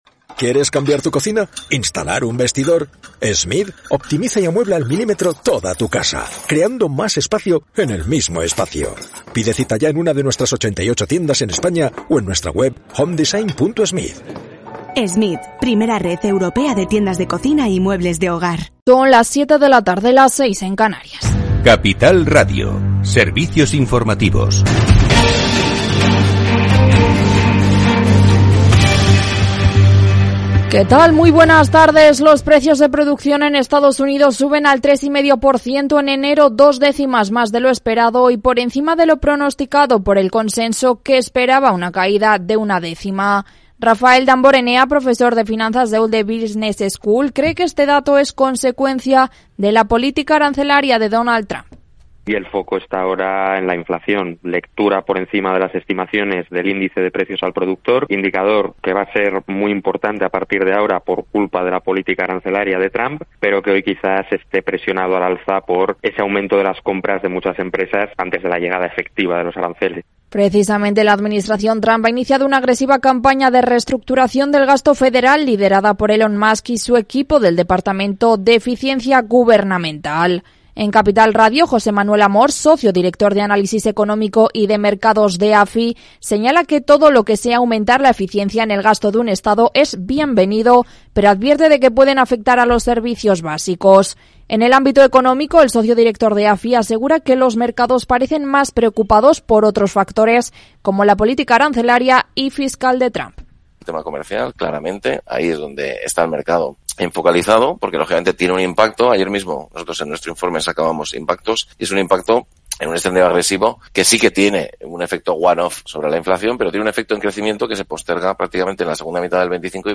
Programa diario dedicado a las pymes, profesionales, autónomos y emprendedores. Hablamos de Big Data y de crowdfunding, de management y coaching, de exportar e importar, de pedir créditos a los bancos y de empresas fintech. Los especialistas comparten sus experiencias, sus casos de éxito y sus fracasos. Todo en un tono propio de un Afterwork, en el que podrás hacer un poco de networking y hacer negocios más allá de la oficina.